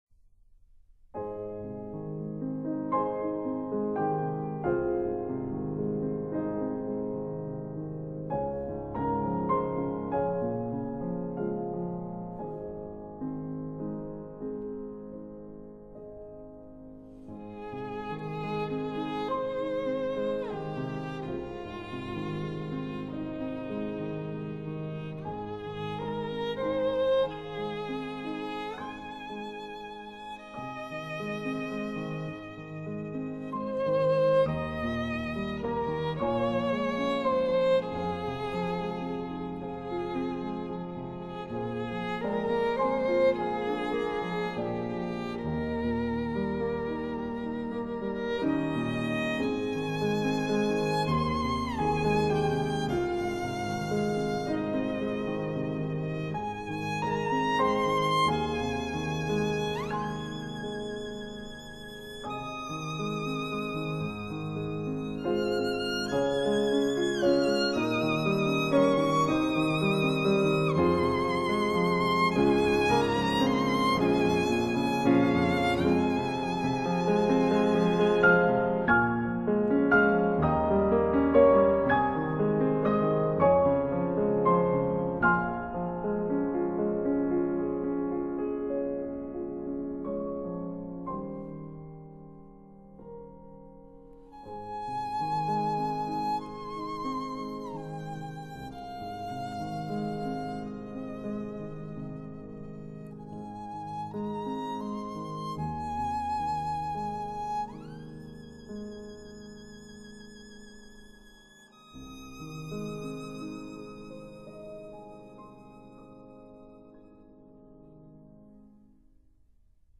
最迷人的 如丝绸般细致的小提琴
小提琴
鋼琴